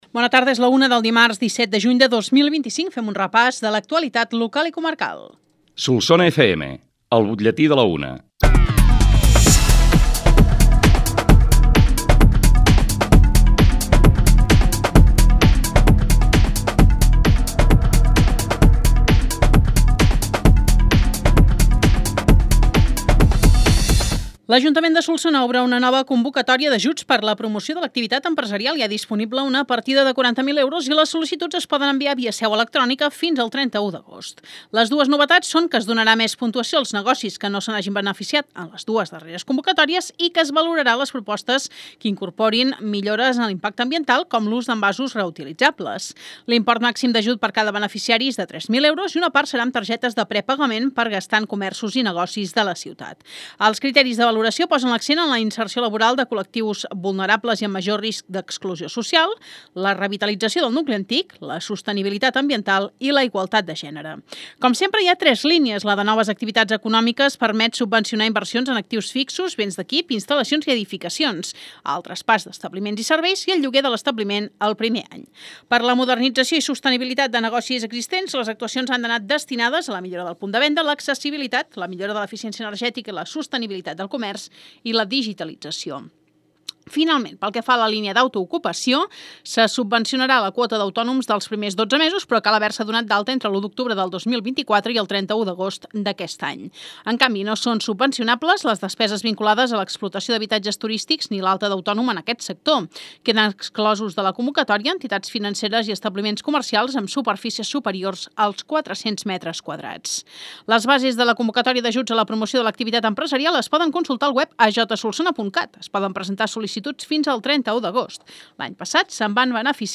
L’ÚLTIM BUTLLETÍ